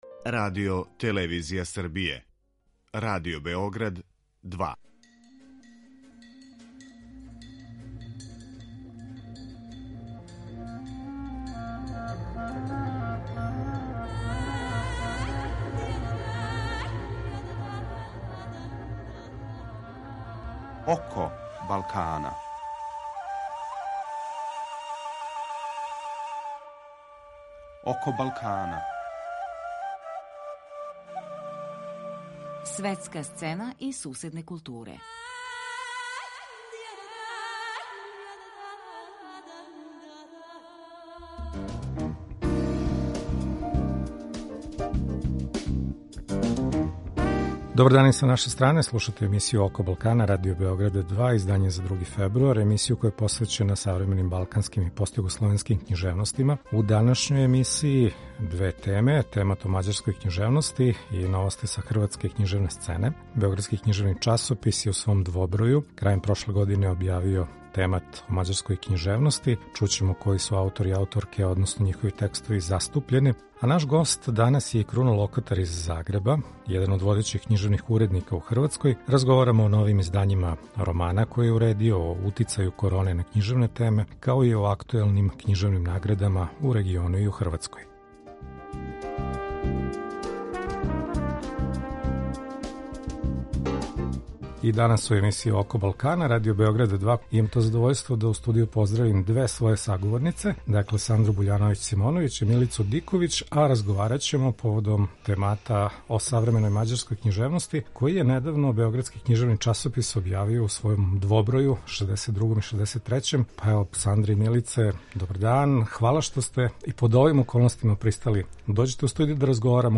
Радио Београд 2